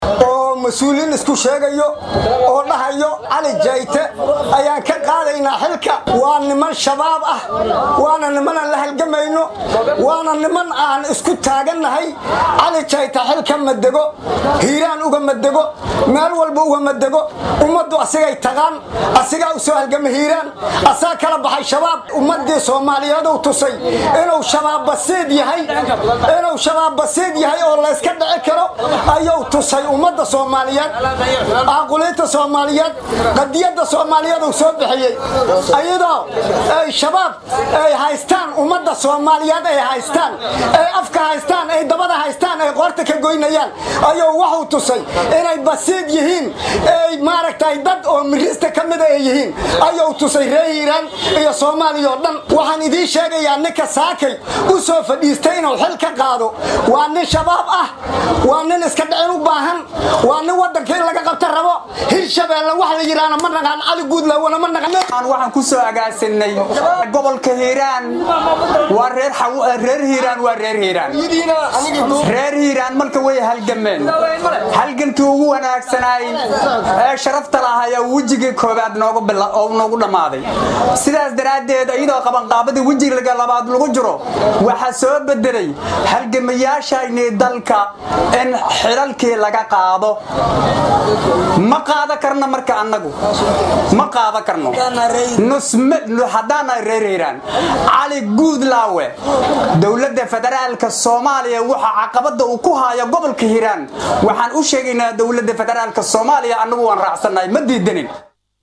DHAGEYSO:Dadweyne dibadbaxaya oo xirtay qaybo ka mid ah waddooyinka magaalada Beledweyn
Shacabka-Hiiraan-Bannaanbax.mp3